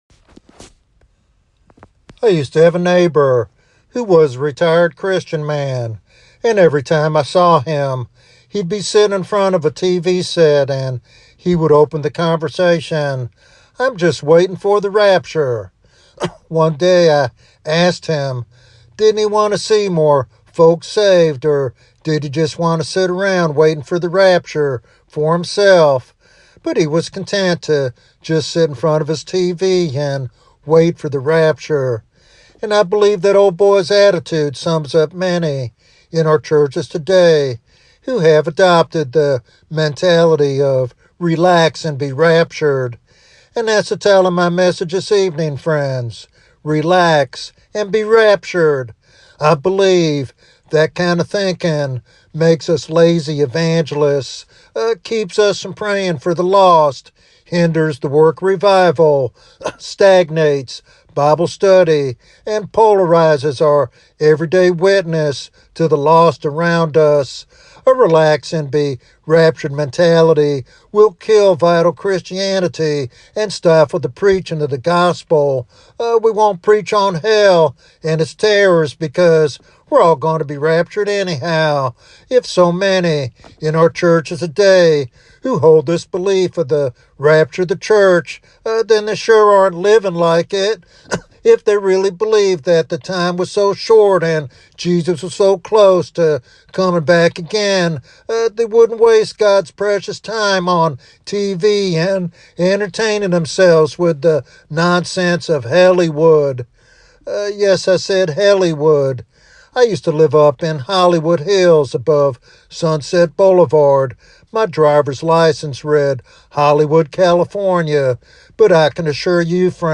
In this challenging sermon